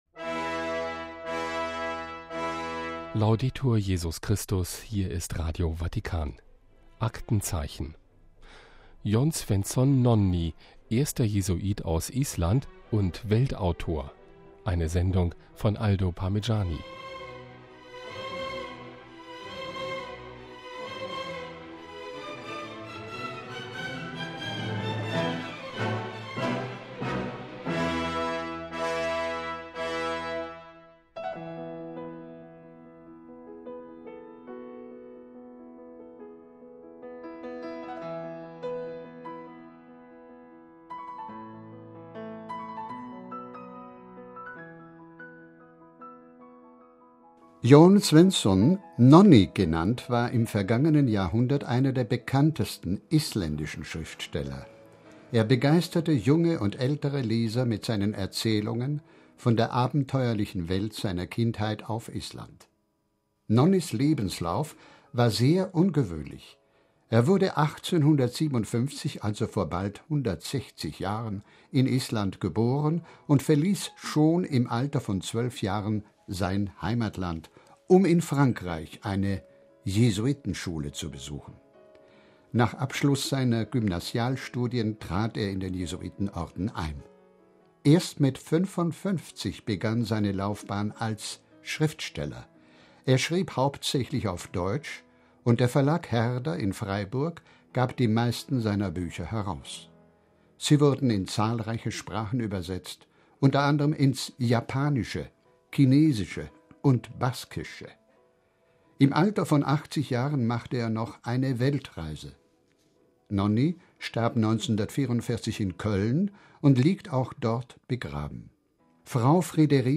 So wie heute bei Radio Vatikan.